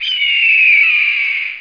hawk1.mp3